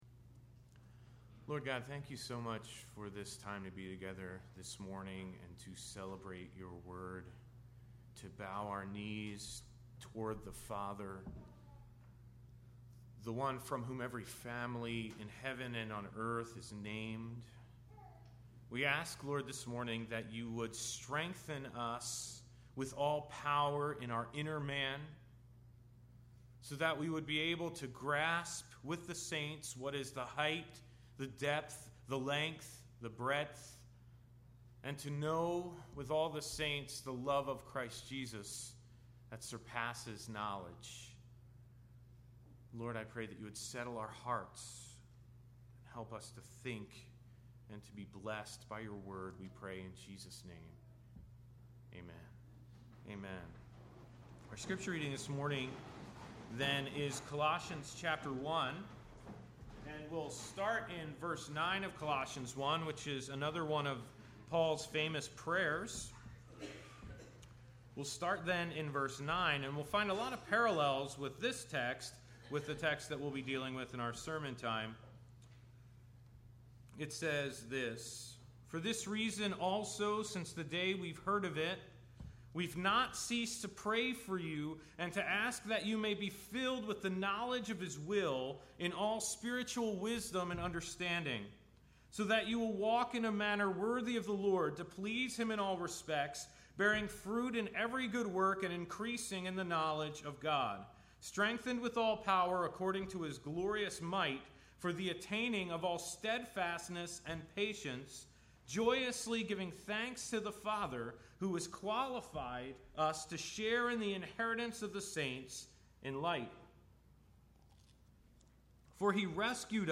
Immanuel Bible Church: Sermon Audio » 2011 » November